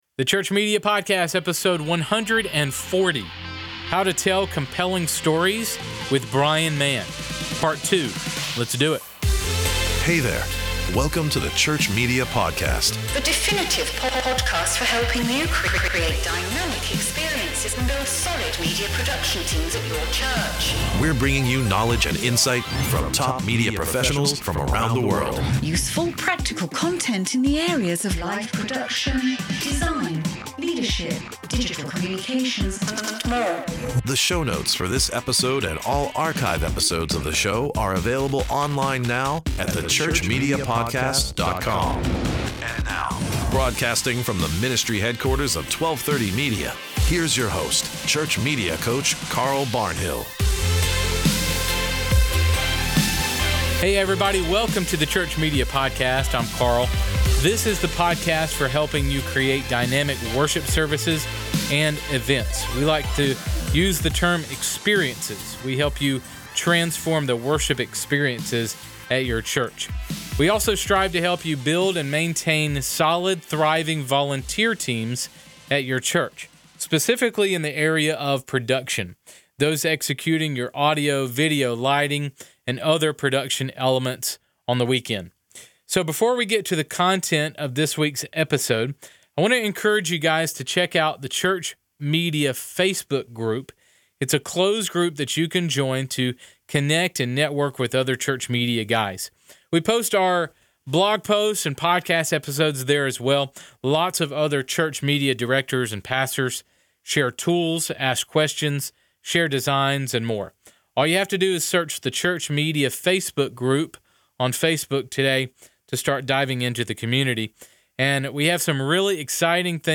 Find out in this interview, how to better capture stories for our worship experiences.